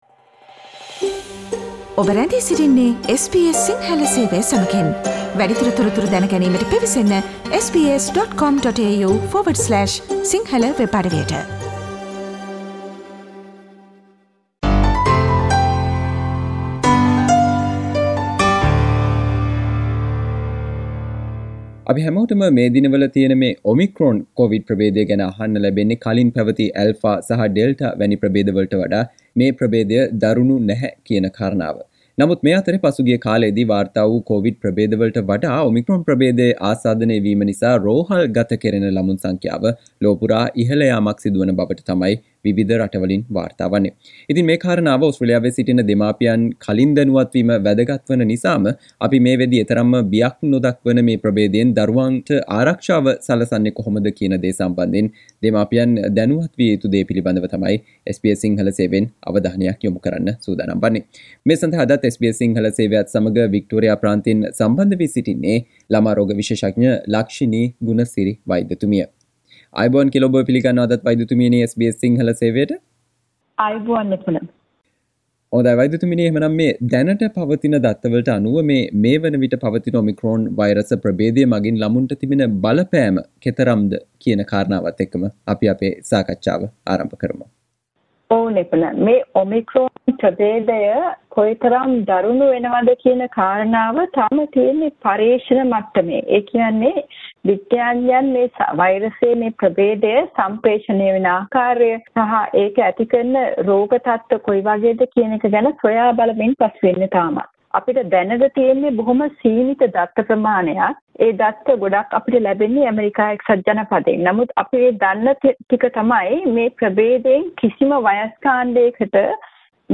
Omicron කොවිඩ් ප්‍රභේදය හේතුවෙන් මේ වනවිට ලොව පුරා ළමුන් රෝහල්ගත කිරීමේ ඉහල යාමක් වාර්තා වේ. මේ හේතුවෙන් දරුවන් ගේ ආරක්ෂාව සැලසීම සම්බන්ධයෙන් ඕස්ට්‍රේලියාවේ වෙසෙන දෙමාපියන් අවධානය යොමු කල යුතු කරුණු පිළිබඳව SBS සිංහල සේවය සිදුකල සාකච්චාවට සවන්දෙන්න